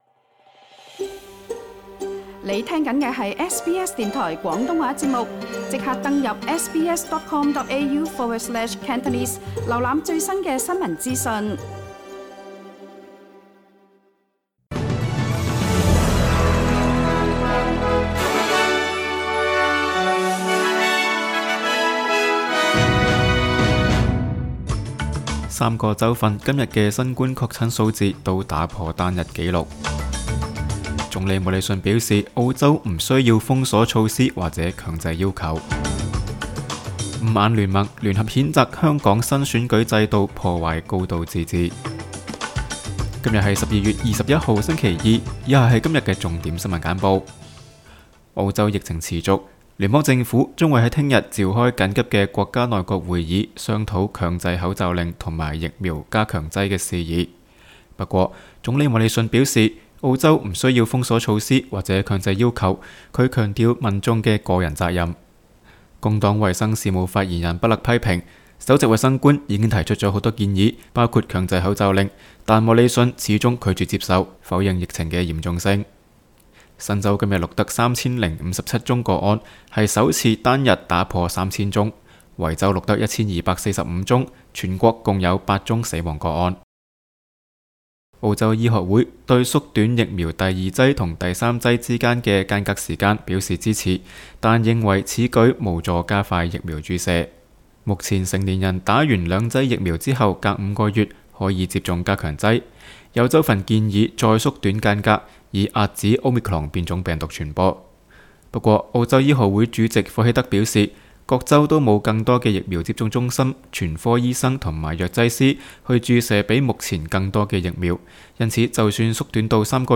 SBS 新聞簡報（12月21日）